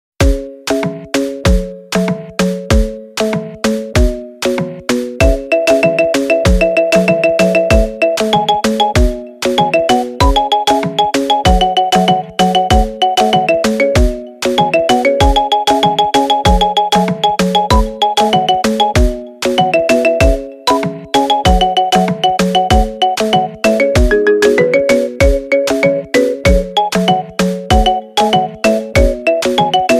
ماريمبا ميكس